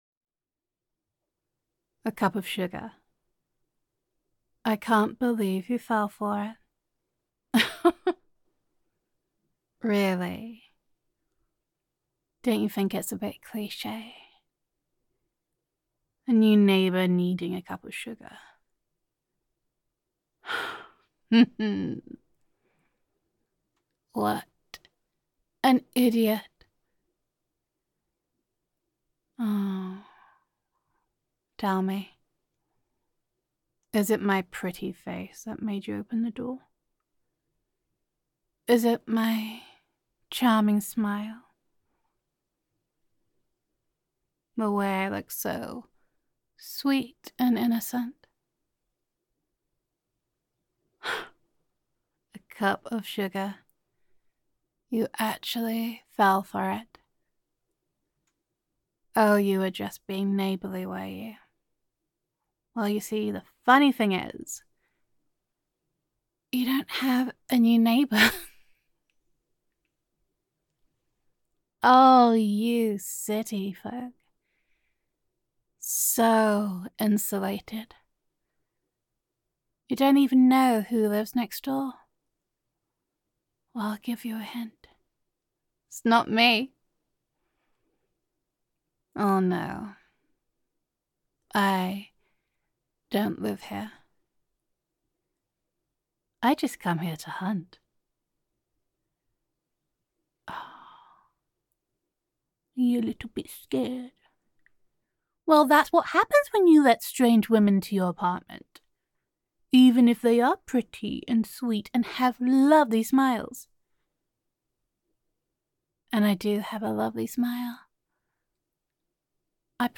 [F4A] A Cup of Sugar [Sweet and Innocent Looking][Be More Careful Who You Let Inside][Do You Know Your Neighbours][Gender Neutral][A New Neighbour Comes Knocking for More Than a Cup of Sugar]